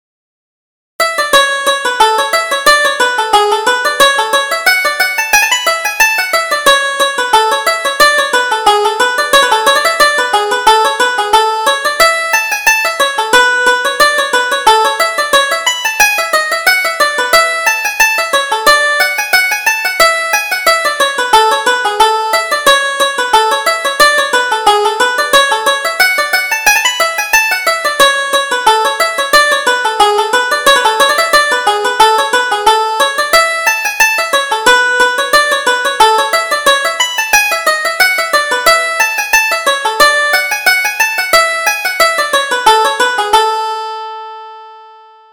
Reel: The Spinner's Delight